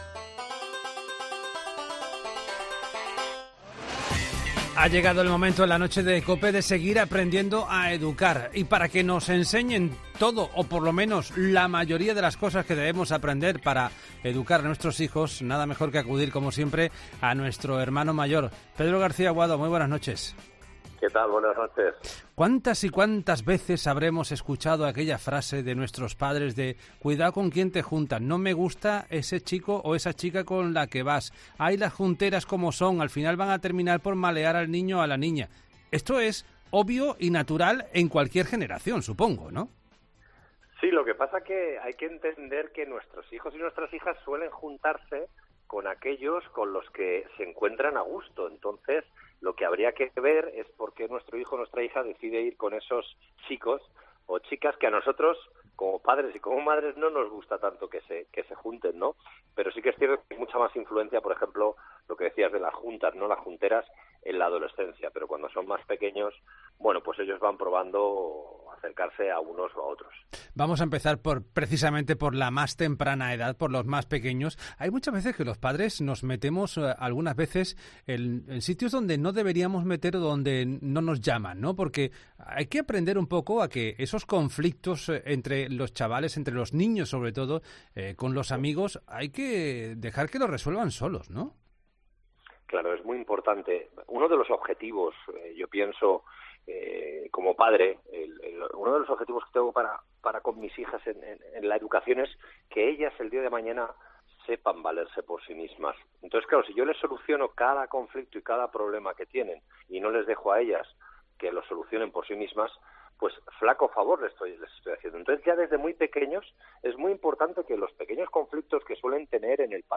El intervencionista familiar de ‘La Noche de COPE’, Pedro García Aguado, explica cómo hacer que nuestros hijos cuiden con las personas que se relacionan.